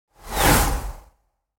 دانلود آهنگ مسابقه 3 از افکت صوتی حمل و نقل
جلوه های صوتی
برچسب: دانلود آهنگ های افکت صوتی حمل و نقل دانلود آلبوم صدای مسابقه ماشین از افکت صوتی حمل و نقل